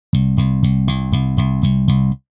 SLAP縺ｯ隕ｪ謖縺ｧ蠑ｦ繧貞娼縺阪∵欠縺ｧ蠑輔▲蠑ｵ繧句･乗ｳ輔〒縺吶ょ鴨蠑ｷ縺上ヱ繝ｼ繧ｫ繝繧ｷ繝悶↑髻ｳ縺檎音蠕ｴ縺ｧ縲∵･ｽ譖ｲ縺ｫ繝繧､繝翫Α繧ｯ繧ｹ繧繧ｰ繝ｫ繝ｼ繝ｴ諢溘ｒ蜉縺医ｋ縺薙→縺後〒縺阪∪縺吶